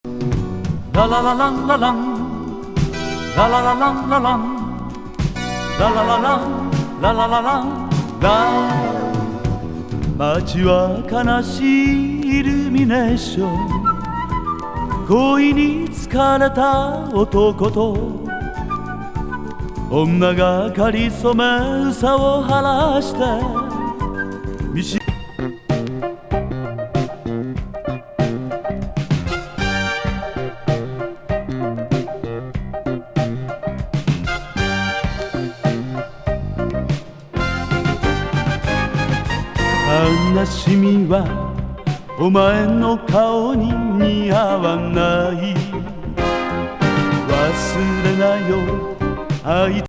笛、琴、鼓にエキセントリック・女性ボーカル